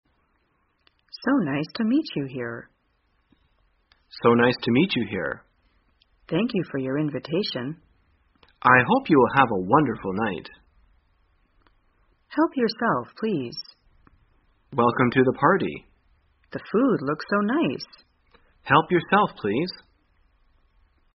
在线英语听力室生活口语天天说 第279期:怎样在聚会交谈的听力文件下载,《生活口语天天说》栏目将日常生活中最常用到的口语句型进行收集和重点讲解。真人发音配字幕帮助英语爱好者们练习听力并进行口语跟读。